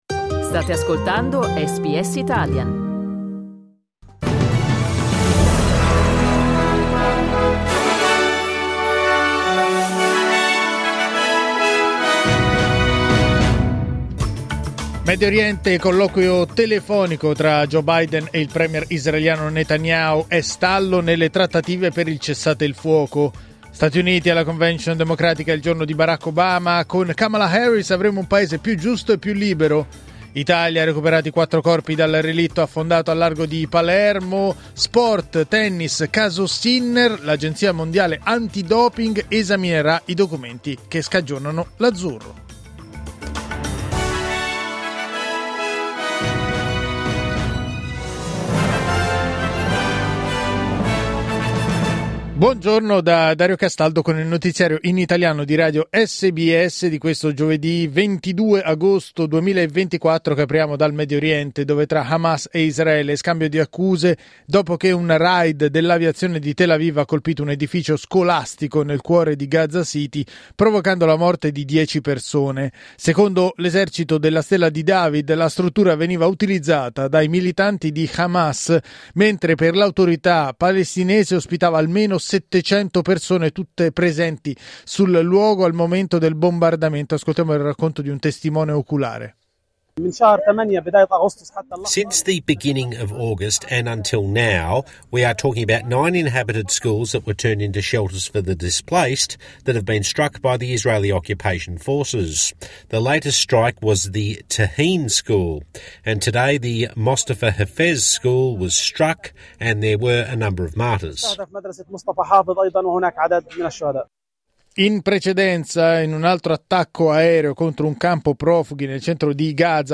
Giornale radio giovedì 22 agosto 2024
Il notiziario di SBS in italiano.